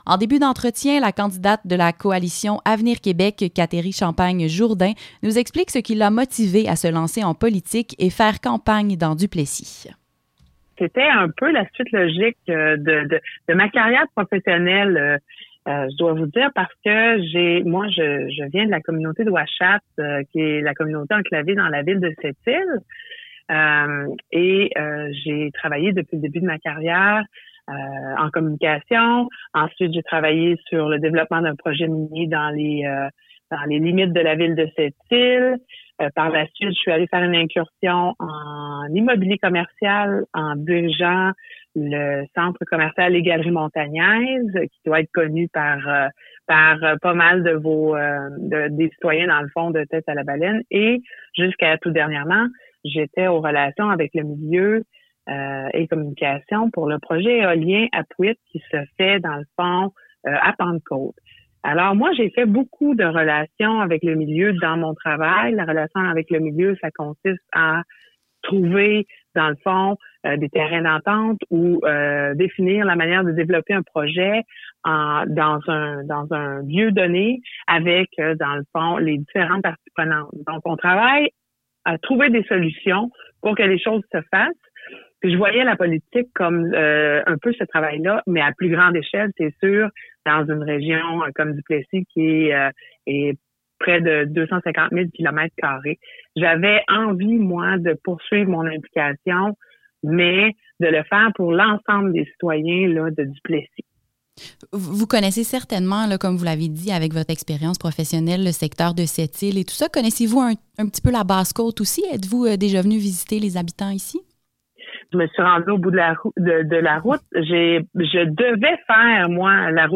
La candidate de la Coalition Avenir Québec Kateri Champagne Jourdain voit sa volonté de représenter les citoyens de Duplessis comme une suite logique dans son parcours professionnel. Elle explique pourquoi dans un entretien accordé à CJTB.
Kateri-segment-radio.mp3